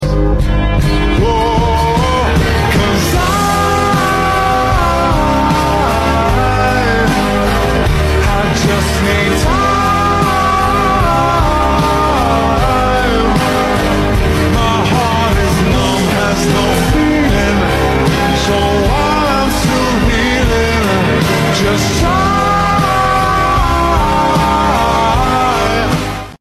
kegiatan crew setelah dapet job di dekat pantai...